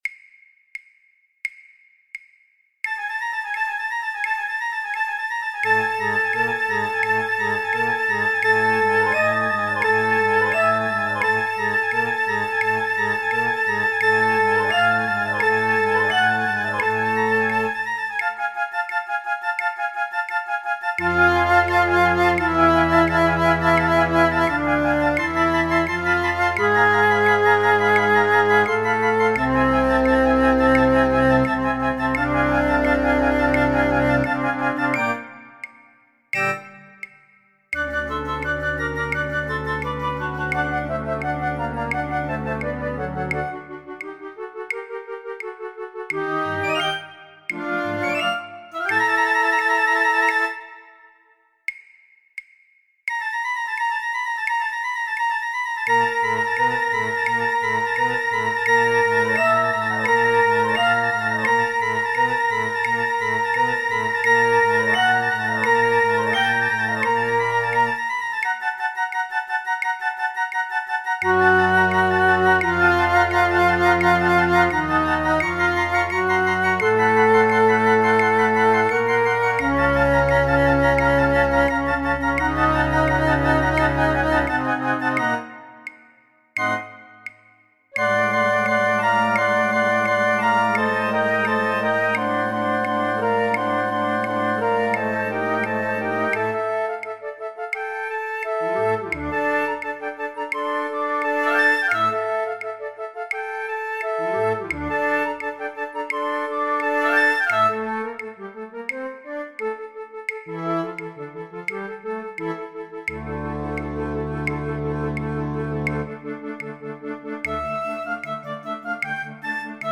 Alle partijen samen (in tempo):